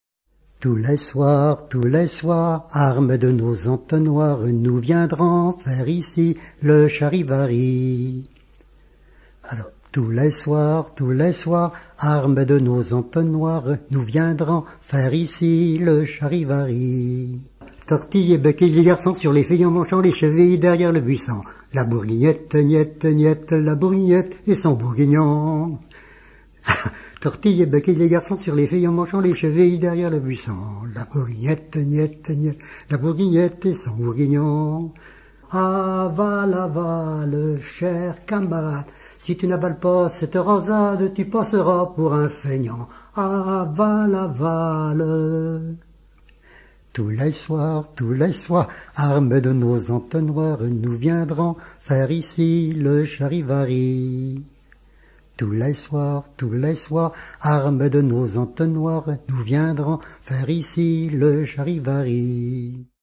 Chant à boire de charivari
chants brefs précédés d'un charivari